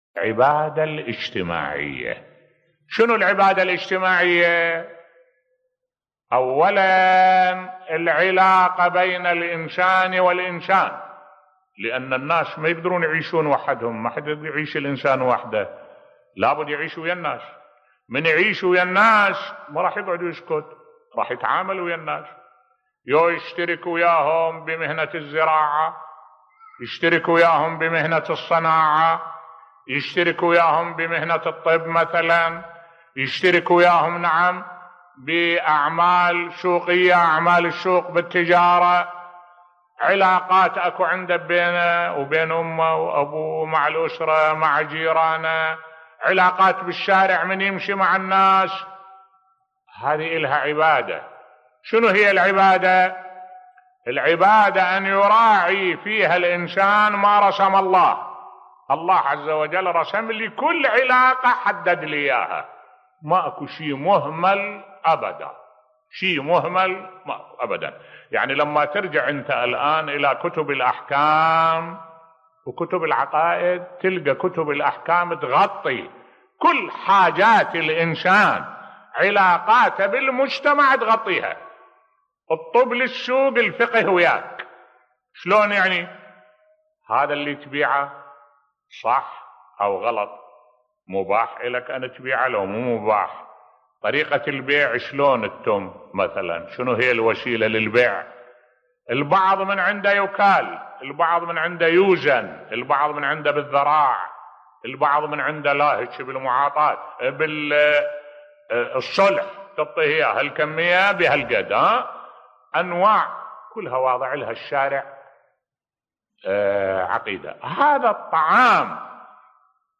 ملف صوتی الإسلام أعطى لكل شيء حكماً بصوت الشيخ الدكتور أحمد الوائلي